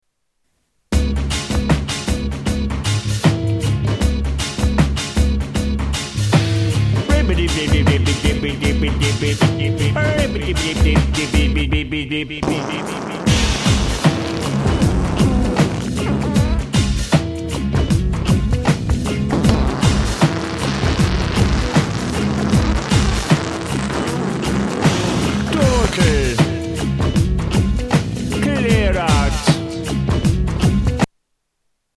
Tags: Comedians Mr Methane Fart Fart Music Paul Oldfield